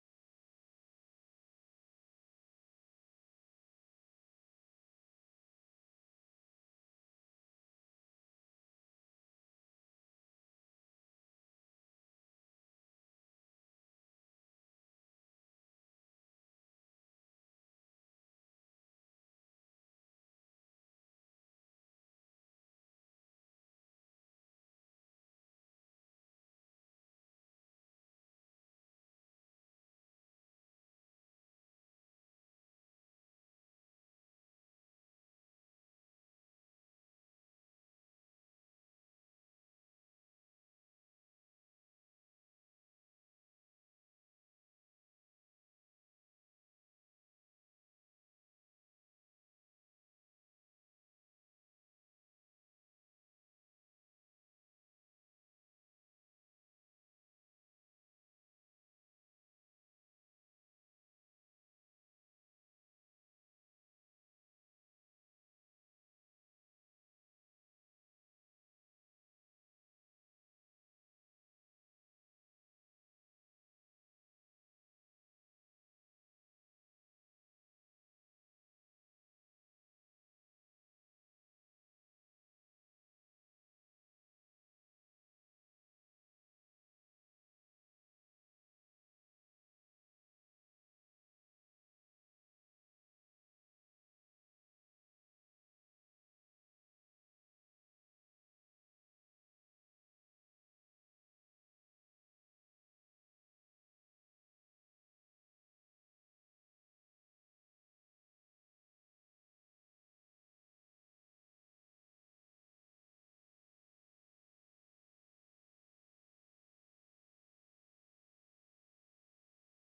29th of June 2025 FWC Praise and Worship